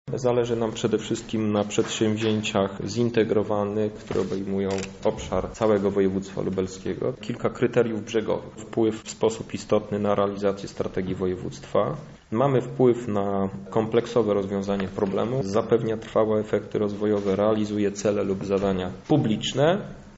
– Chcemy jednak, aby pieniądze dostały inwestycje inne, niż do tej pory – zaznacza marszałek Krzysztof Hetman